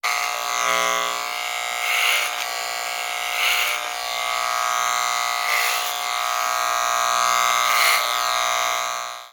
На этой странице собраны звуки работы машинки для стрижки волос в высоком качестве.
В коллекции представлены разные модели машинок: от тихих до более шумных, с различными режимами работы.
звук машинки для стрижки волос